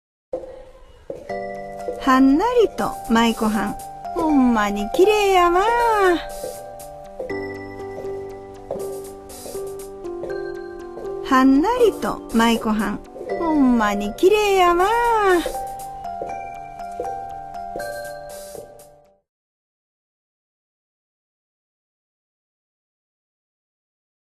• 読み上げ
市田ひろみ 語り●市田ひろみ